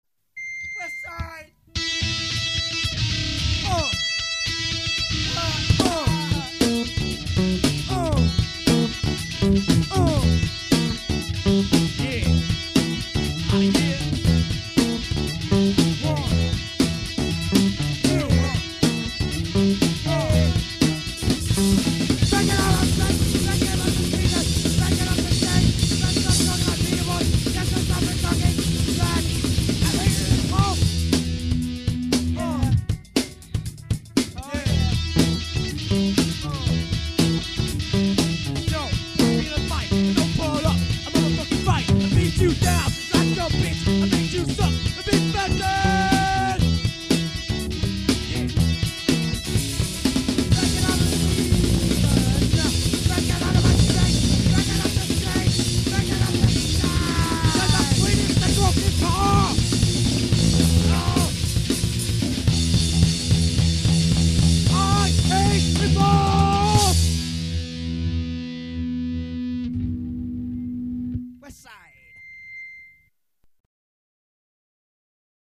----East coast/West coast Hardcore, Gregorian Chant----